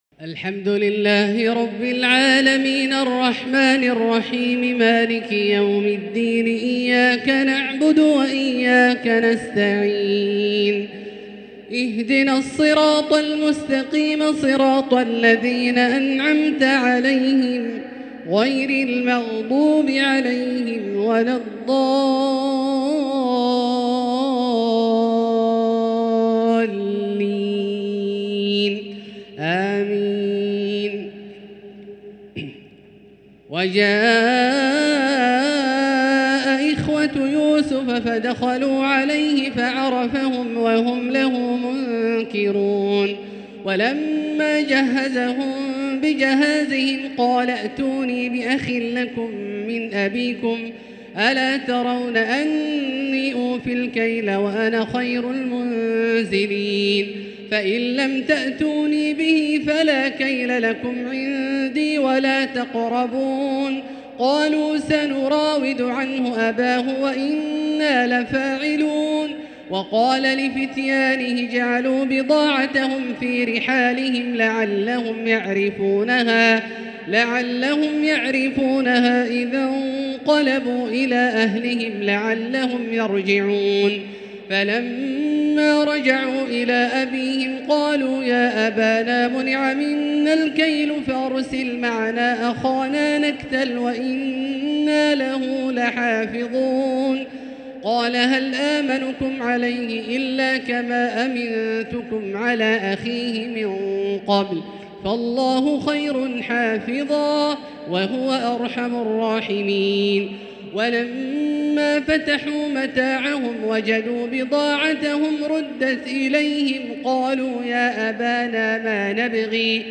تراويح ليلة 17 رمضان 1444هـ من سورتي يوسف (54-111) و الرعد (1-11) | Taraweeh 17st night Ramadan 1444H Surah Yusuf and Ar-Rad > تراويح الحرم المكي عام 1444 🕋 > التراويح - تلاوات الحرمين